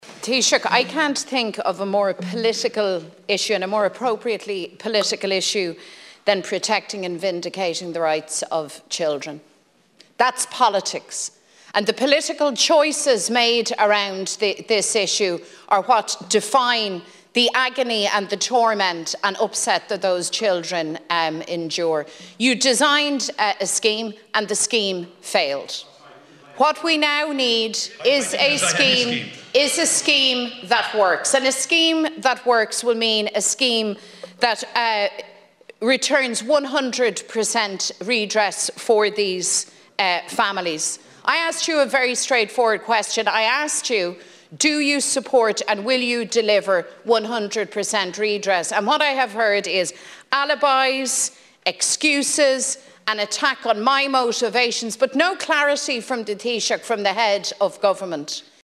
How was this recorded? There’s been a heated debate in the Dail this afternoon on the issue – over what form the scheme will take and if the Government will grant 100% redress.